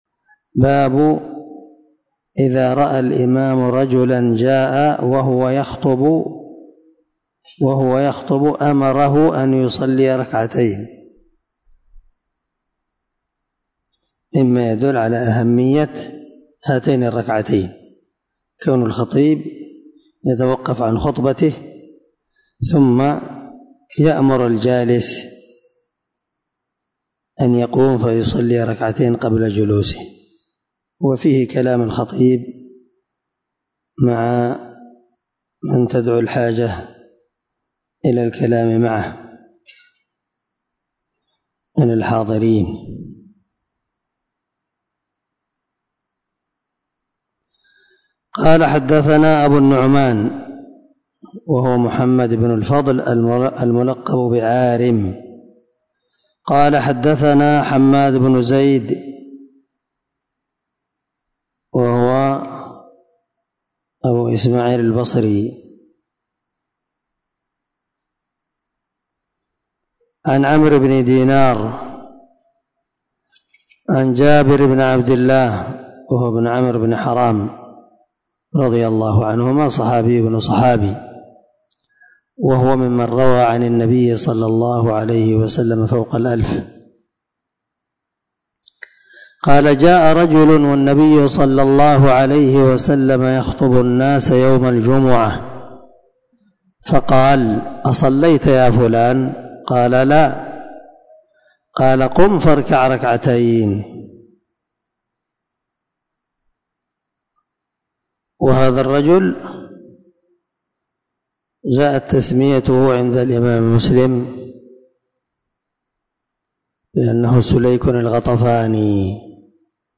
عنوان الدرس: